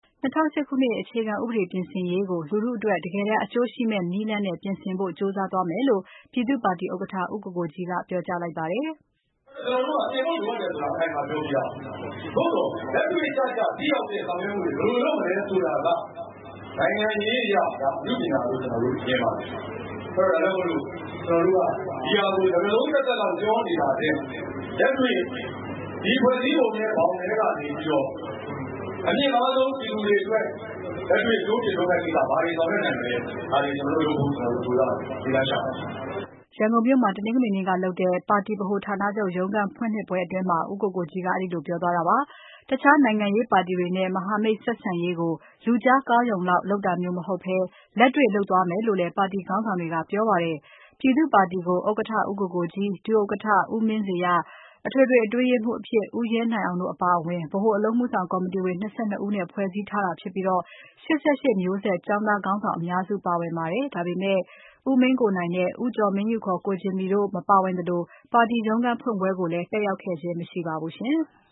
ပြည်သူ့ပါတီ သတင်းစာရှင်းလင်း
ရန်ကုန်မြို့ မှာ တနင်္ဂနွေနေ့ကလုပ်တဲ့ ပါတီဗဟိုဌာနချုပ် ရုံးခန်းဖွင့်လှစ်ပွဲအတွင်း ဦးကိုကိုကြီးက အဲဒီလိုပြောသွားတာပါ။